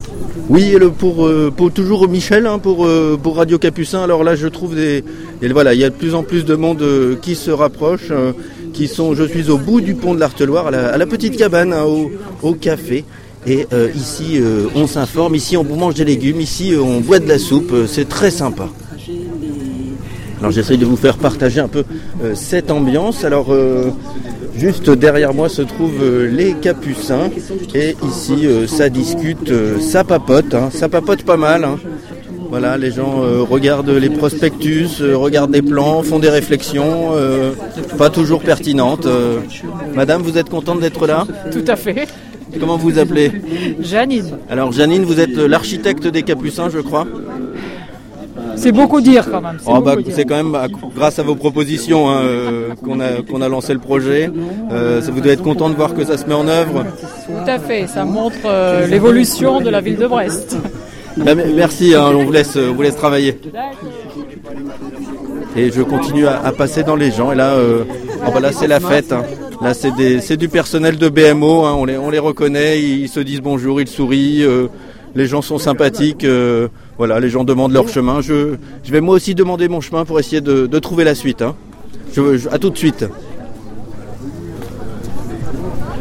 Reportage officiel
Au café Nungesser